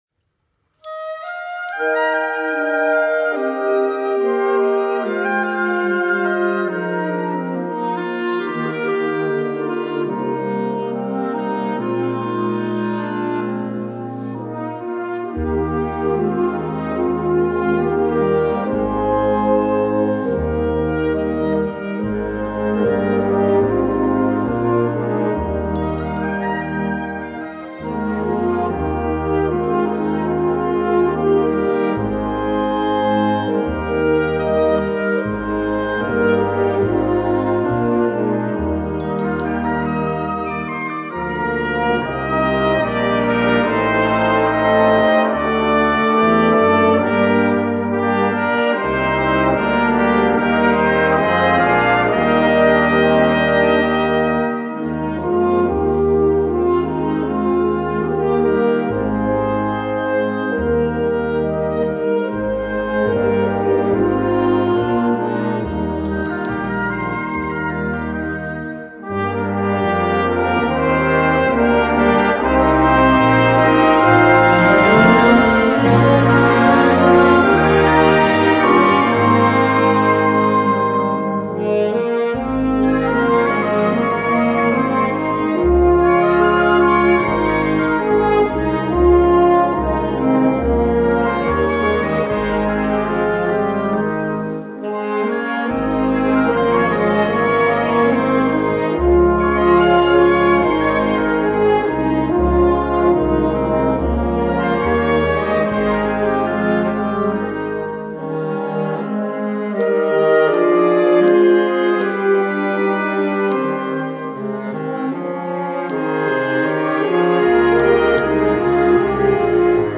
Gattung: Konzertwerk ohne Chor
Besetzung: Blasorchester